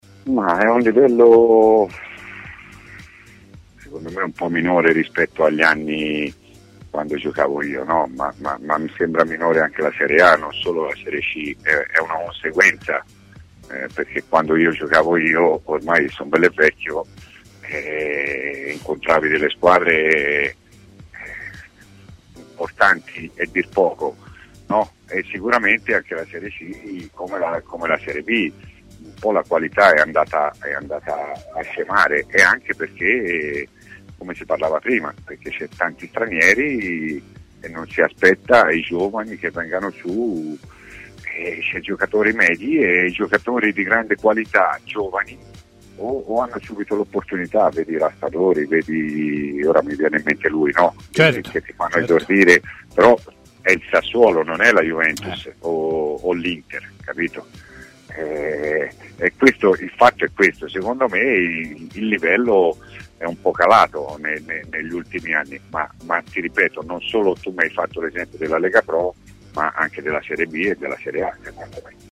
Ospite di TMW Radio